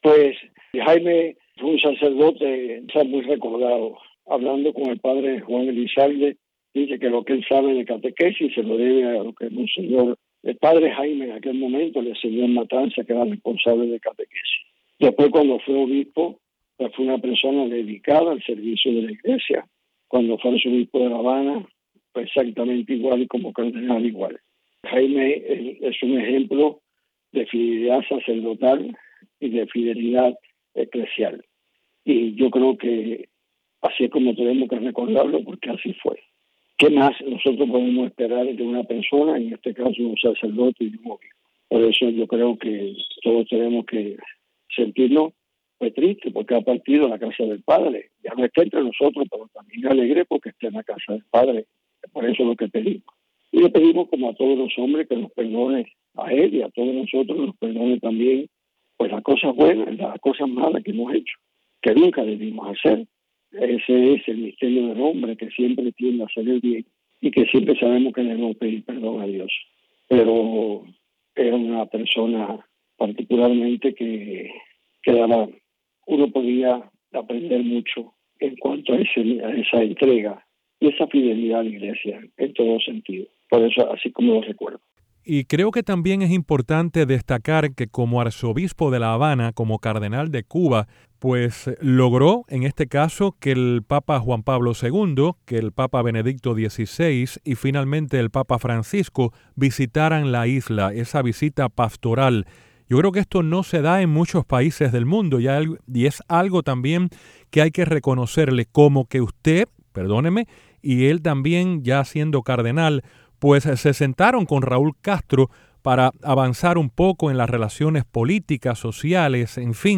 Declaraciones de Monseñor Dionisio García, arzobispo de Santiago de Cuba